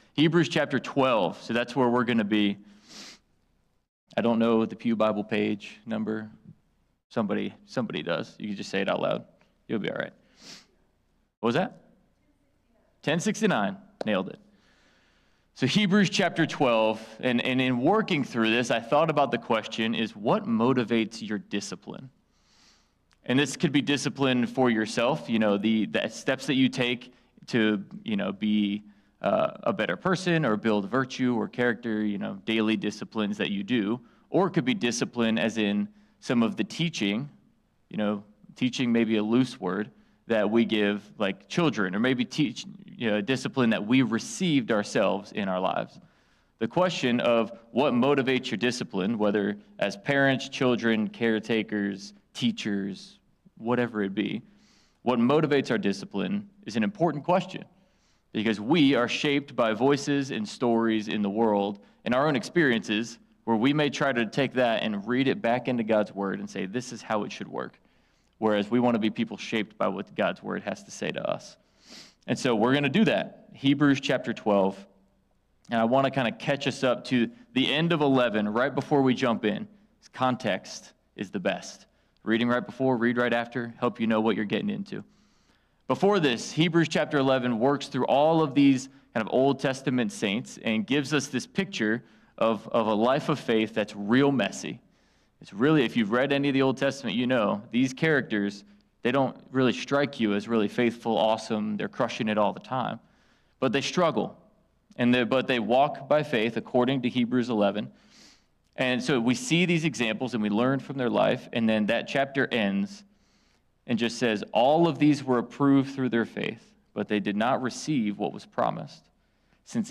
Sermons | Christiansburg Baptist Church | Christiansburg, VA